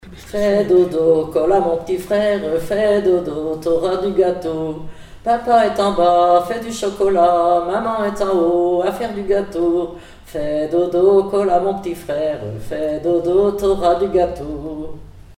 enfantine : berceuse
Pièce musicale inédite